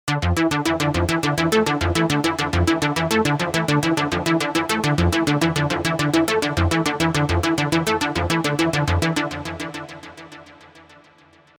Berlin School
In following tutorial we will create some typical Berlin School-like sequences with techniques, which came up in the 1970s by using multiple analog sequencers.
This means in other words: in conjunction with the transposer track G4T4, we will play D Minor for 8 measures, thereafter G Minor, F Minor and finally D Minor again.
In G1T3 we create a sequence, where another typical "vintage technique" is used: skipped steps.
So, we reduced the sequence to 11 steps - and this is the result: